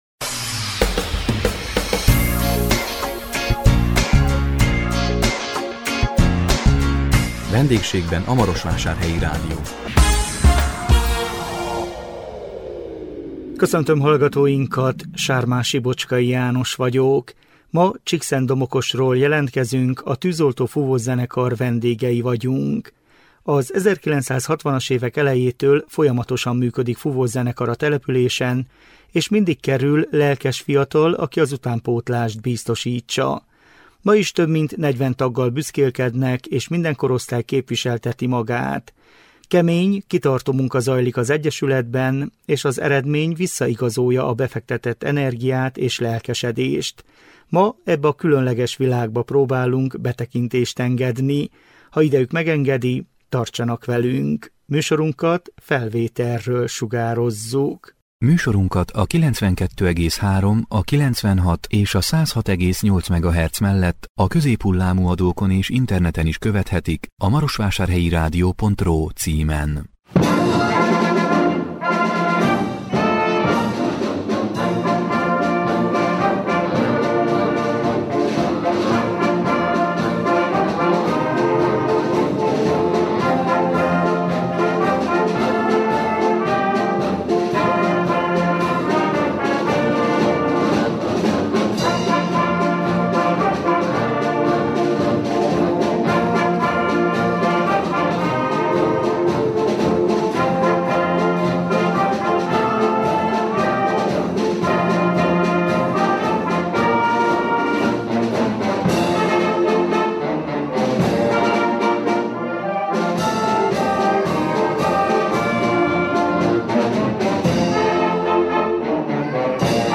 A 2025 május 15-én közvetített VENDÉGSÉGBEN A MAROSVÁSÁRHELYI RÁDIÓ című műsorunkkal Csíkszentdomokosról jelentkeztünk, a Tűzoltó Fúvószenekar vendégei voltunk. Az 1960-as évek elejétől folyamatosan működik fúvószenekar a településen és mindig kerül lelkes fiatal, aki az utánpótlást biztosítsa. Ma is több mint 40 taggal büszkélkednek és minden korosztály képviselteti magát.